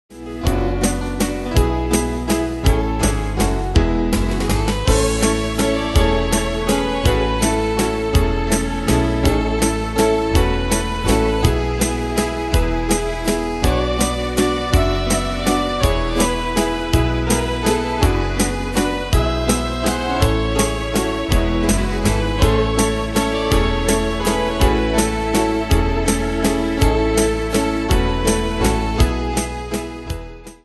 Demos Midi Audio
Style: Country Année/Year: 1970 Tempo: 164 Durée/Time: 2.42
Danse/Dance: Valse/Waltz Cat Id.